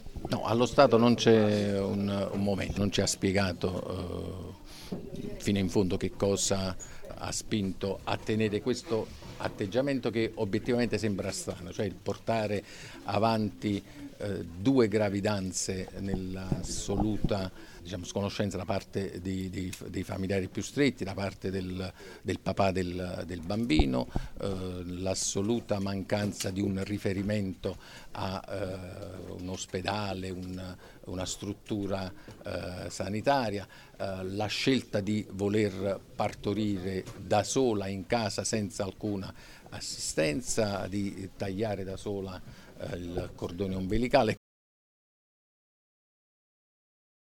È quanto è emerso nel corso di una conferenza stampa tenuta dal Procuratore di Parma, Alfonso D’Avino, nella mattinata di oggi, venerdì 20 settembre, nella città Ducale.
il Procuratore della Repubblica, Alfonso D’Avino